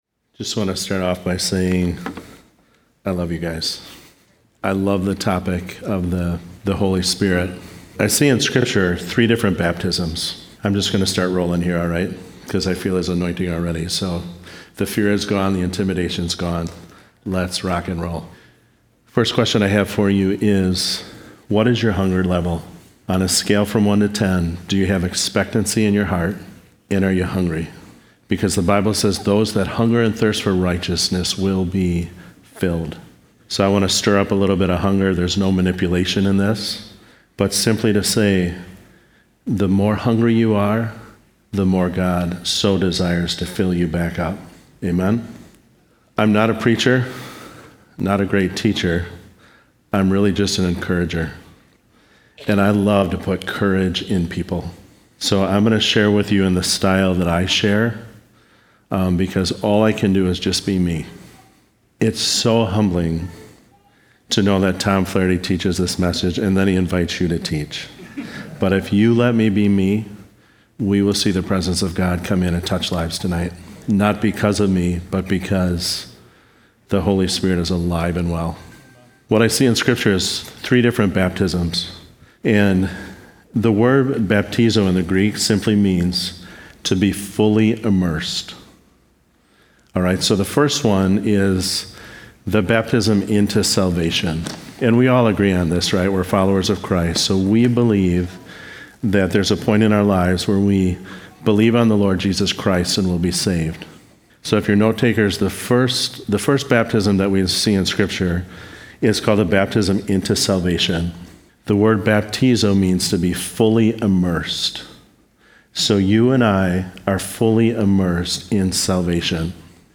A message given at a Tuesday Night Prayer.